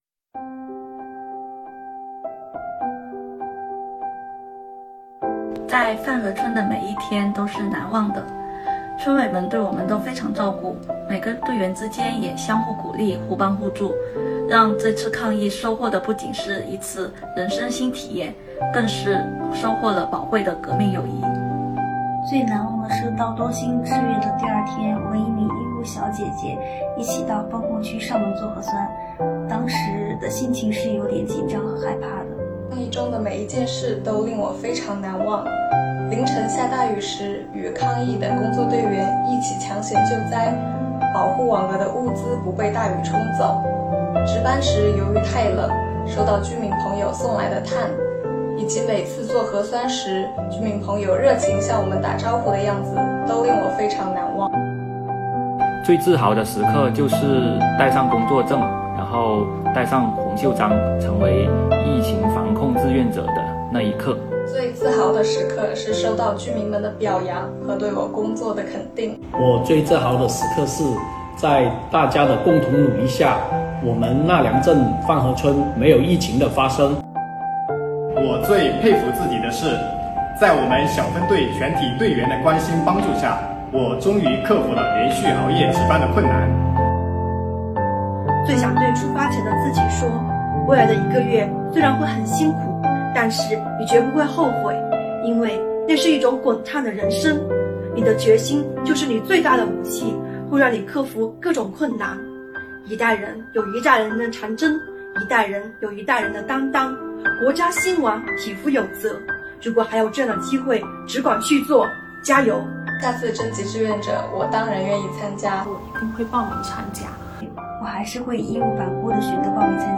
本次榜样讲堂邀请了全市税务系统驰援东兴、峒中、那良疫情防控工作人员代表，现场讲述战疫故事，展示榜样力量。
线上采访志愿者环节